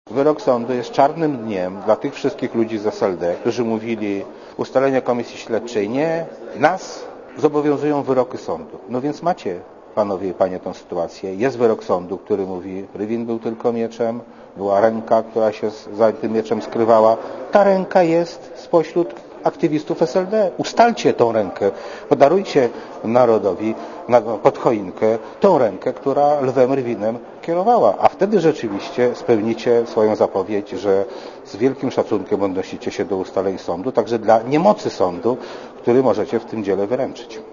Posłuchaj komentarza Krzysztofa Janika Posłuchaj komentarza Tomasza Nałęcza